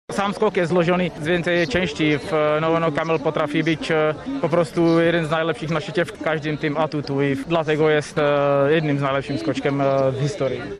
Michal Doleżal, trener reprezentacji Polski powiedział w TVP Info, że Stoch jest zawodnikiem kompletnym.